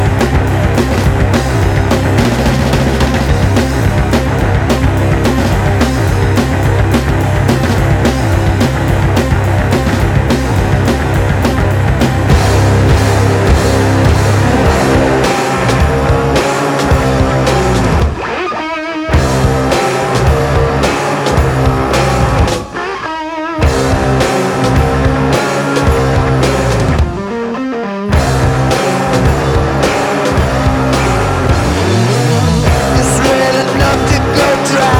Жанр: Рок / Метал
Rock, Metal, Hard Rock